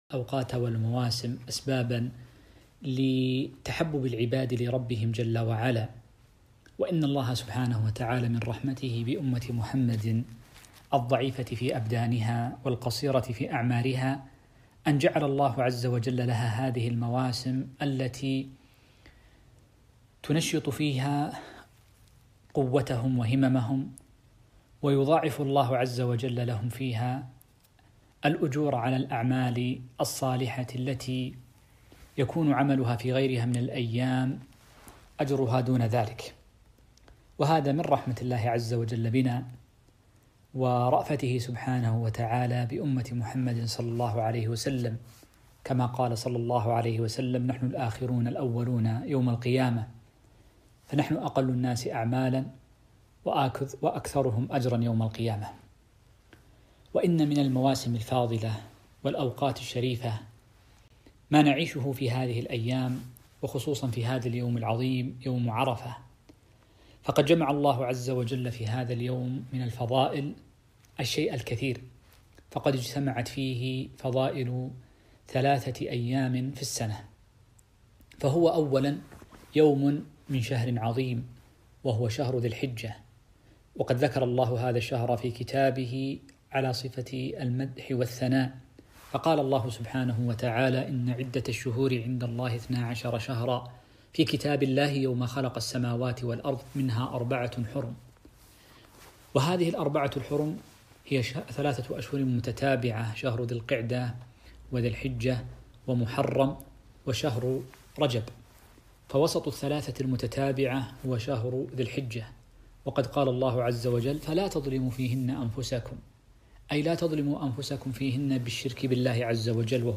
كلمة - فضائل وأحكام يوم عرفة 8 ذو الحجة 1441هــ